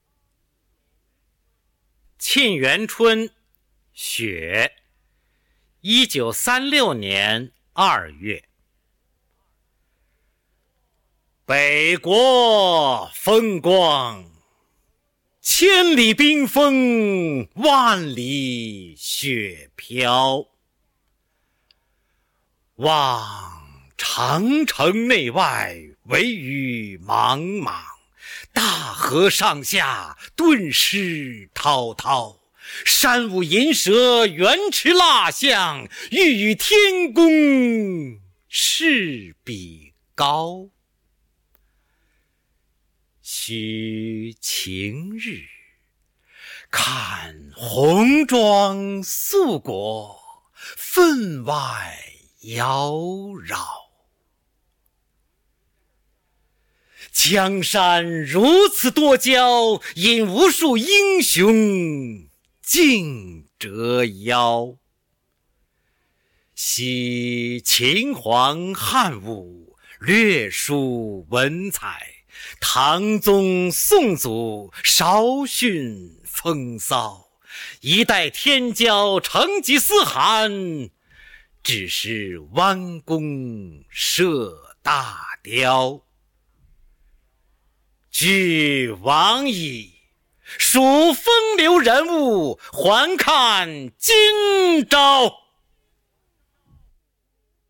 首页 视听 经典朗诵欣赏 网络精选——那些张扬个性的声音魅力